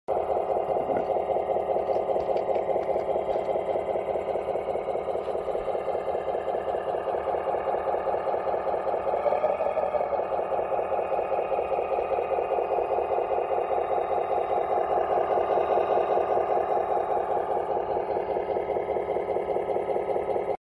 SCANIA 770S V8 RC truck sound effects free download
SCANIA 770S V8 RC truck with tipper trailer and open pipe.